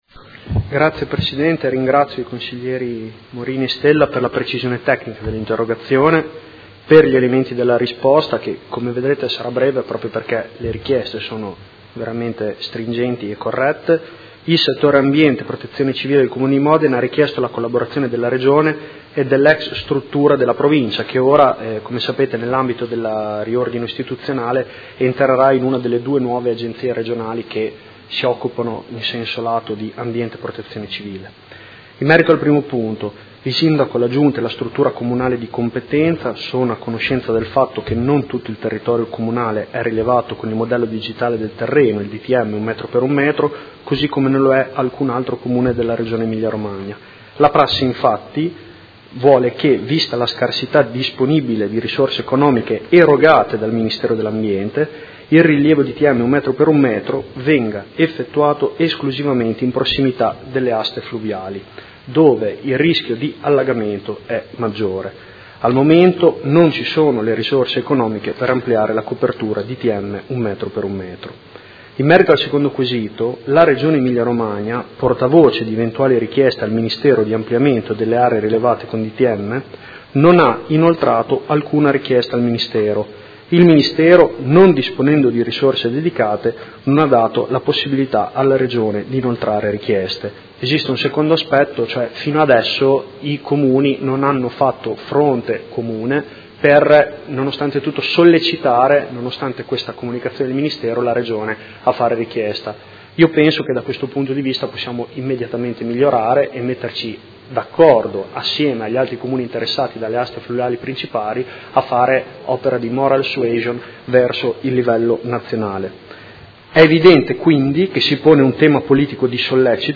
Giulio Guerzoni — Sito Audio Consiglio Comunale
Risponde a interrogazione dei Consiglieri Morini e Stella (P.D.) avente per oggetto: Modello Digitale del Terreno provinciale e prevenzione del rischio idraulico